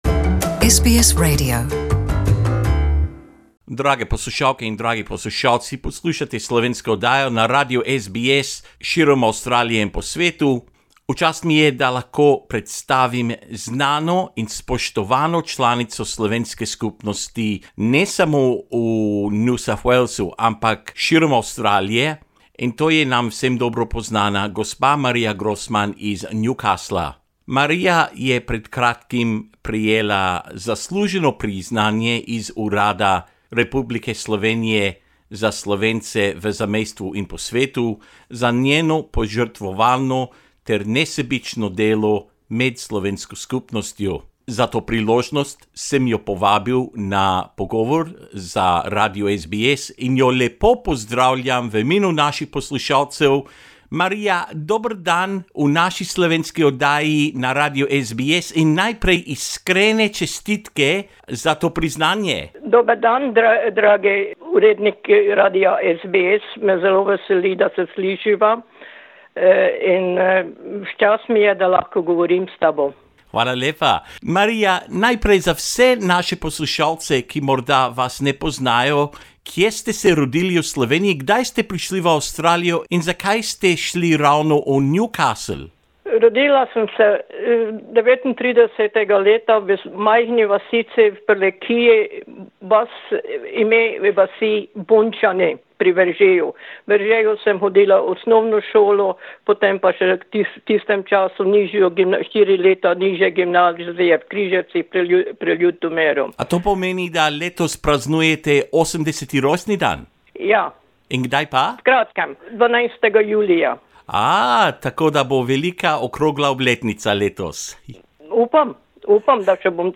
Danes se pogovarjamo